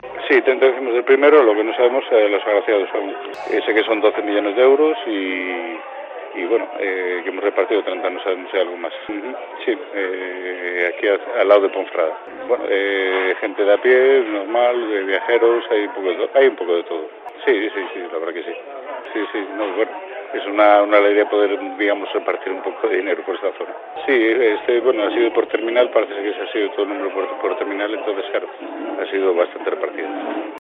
se ha mostrado feliz en los micrófonos de COPE.